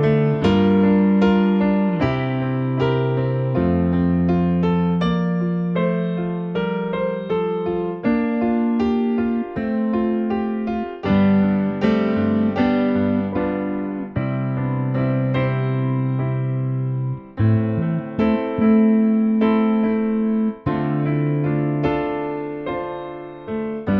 no Backing Vocals Rock 3:33 Buy £1.50